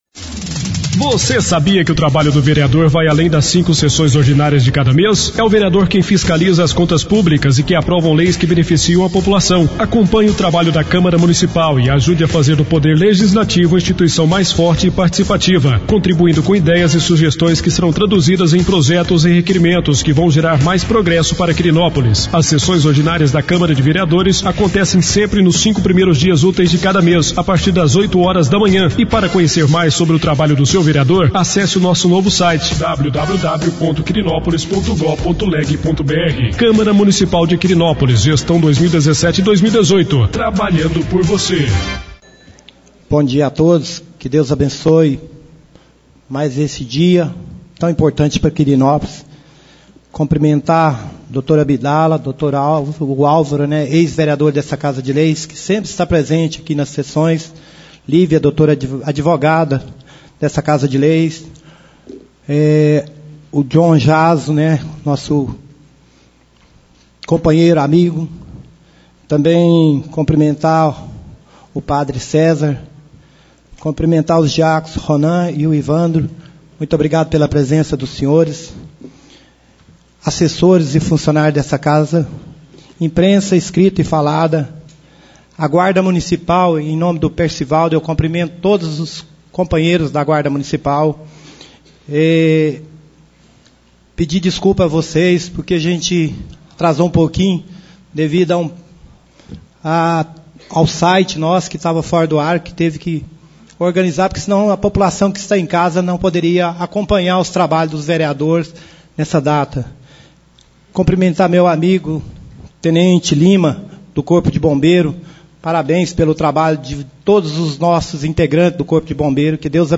4° Sessão Ordinária do Mês de Agosto 2017.